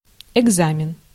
Ääntäminen
France: IPA: /ɛɡ.za.mɛ̃/